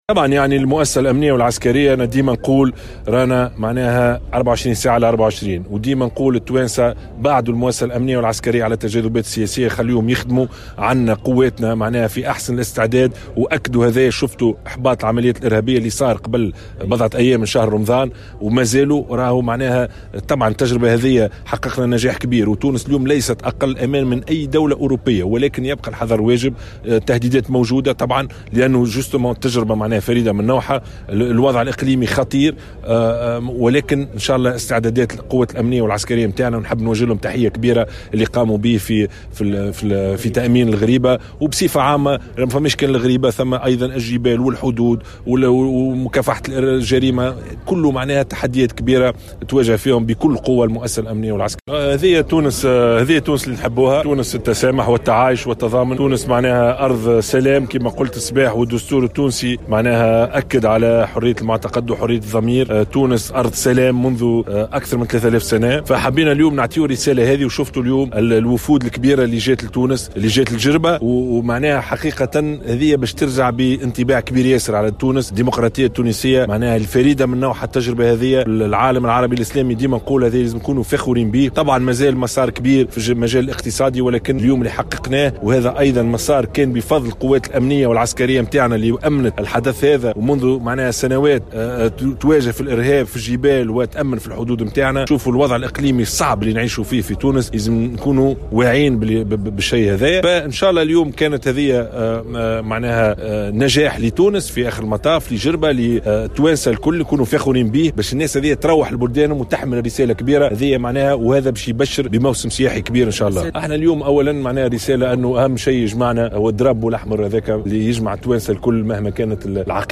ثمّن رئيس الحكومة يوسف الشاهد خلال إشرافه على مائدة إفطار في معبد الغريبة بجربة مساء اليوم مجهودات الوحدات الأمنية والعسكرية، مشددا على ضرورة إبعادهما عن التجاذبات السياسية.